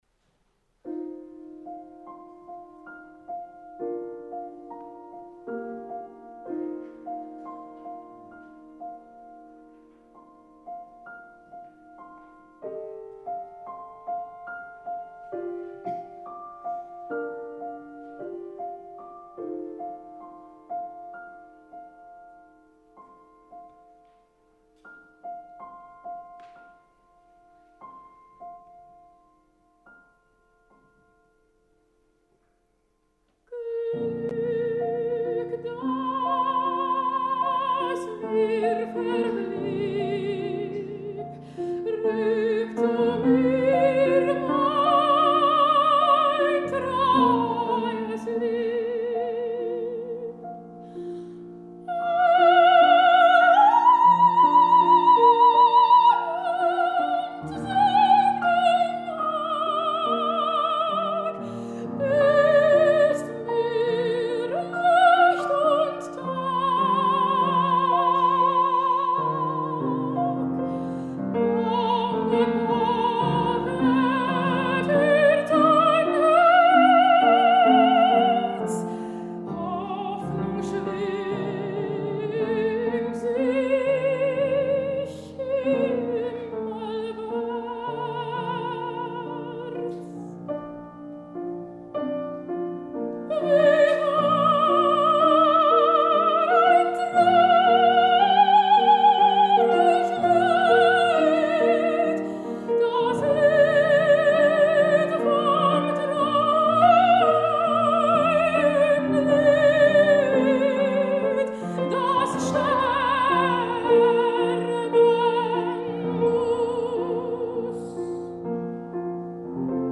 Ara encara fa front a rols de lleugera, però ella és una soprano lírica coloratura posseïdora d’una veu molt bonica, amb un cos que fa preveure una evolució a terrenys més lírics i de consistència vocal i dramàtica més amples.
soprano
piano
The Salon, Melbourne Recital Centre 23 de juliol de 2015